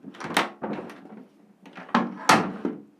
Cerrojo de una puerta de madera
cerrojo
Sonidos: Hogar